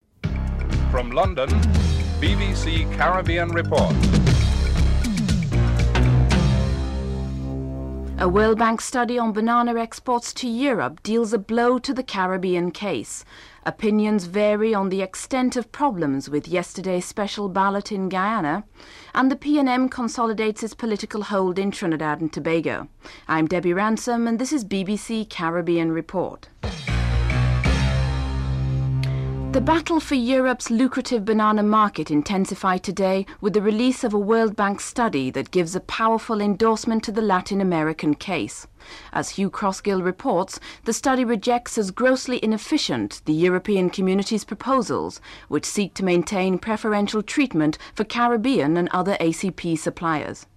1. Headlines (00:00-00:28)
4. Panamanian President Guillermo Endara calls the European Community protectionist trade policies in favour of ACP bananas outmoded and contradictory while addressing the UN General Assembly today. (05:15-05:33)